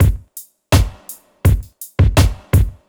• 83 Bpm Reggae Drum Loop Sample G Key.wav
Free drum loop sample - kick tuned to the G note. Loudest frequency: 879Hz
83-bpm-reggae-drum-loop-sample-g-key-DGy.wav